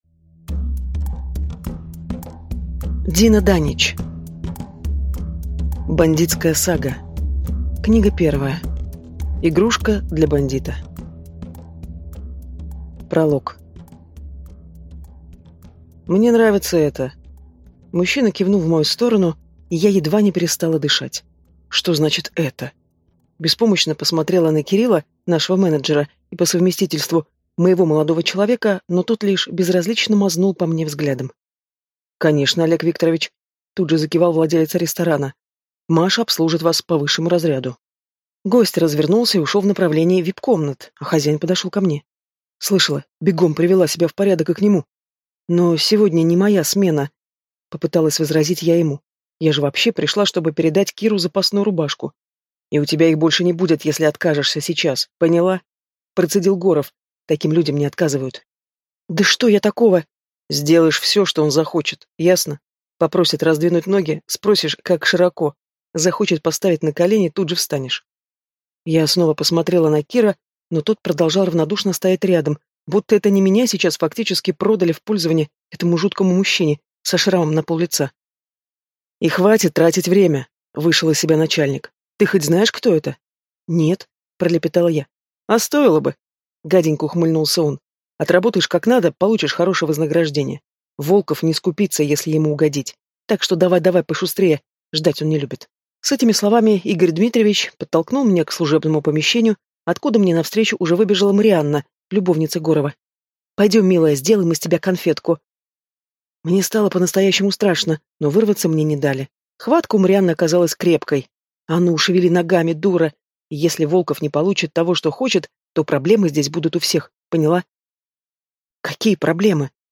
Аудиокнига Игрушка для бандита | Библиотека аудиокниг